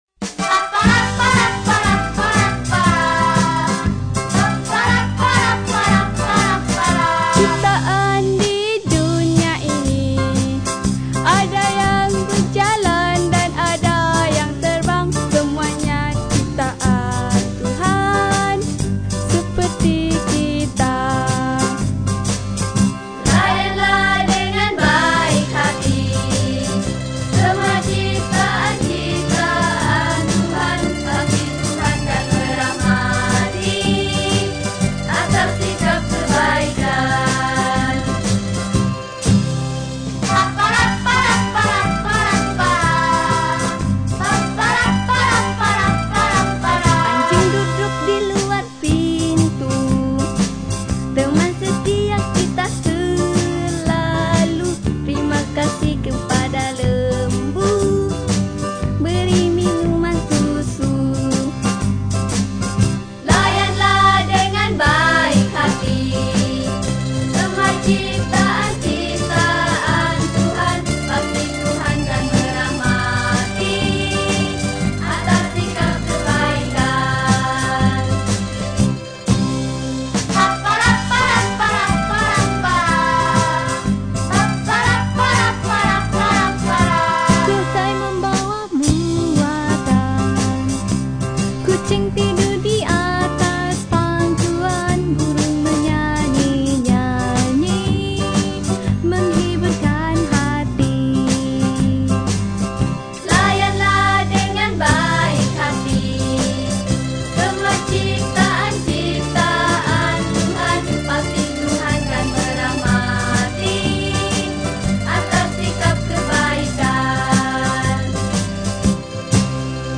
Recorded in Kota Marudo, Sabah, Malaysia (2004)